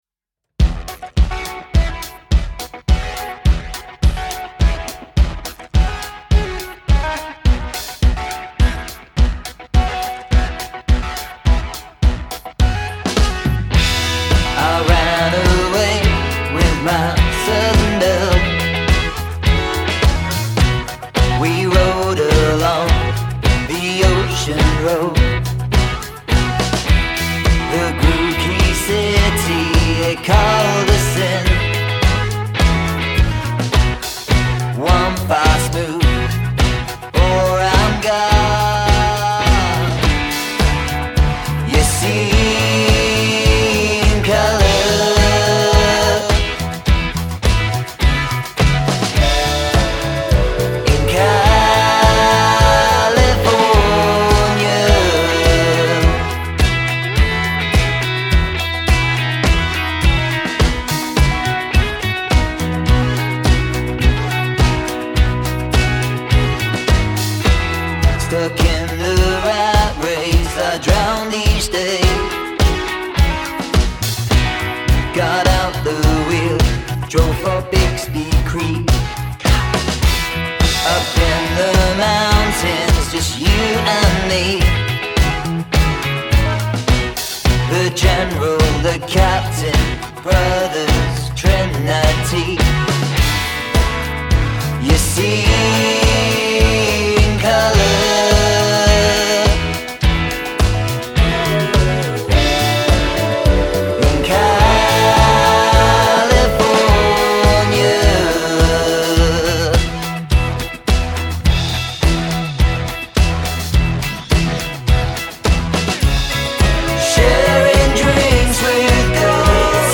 Male Vocal, Electric Guitar, Synth, Bass Guitar, Drums